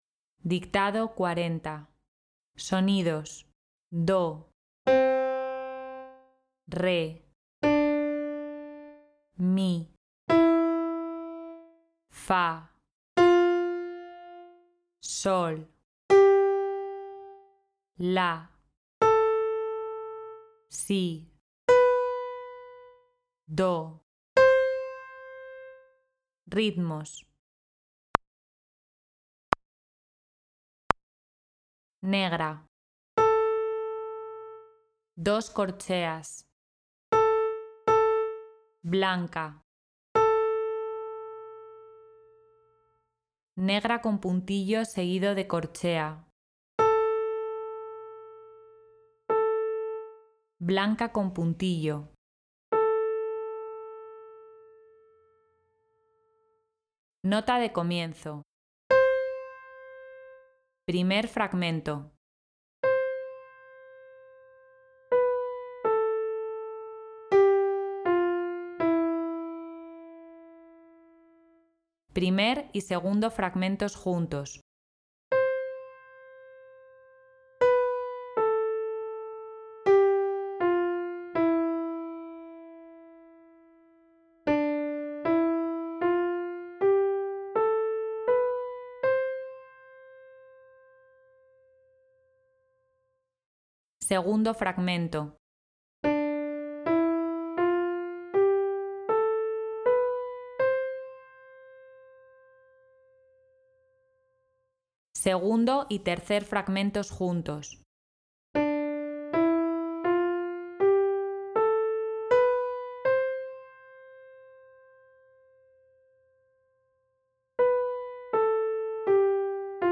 DICTADO 4
Primero escucharás los sonidos y ritmos que pueden aparecer, y a continuación la nota de comienzo. El ejercicio se dictará por fragmentos y al finalizar escucharemos el dictado entero para comprobar el resultado final de nuestra escritura.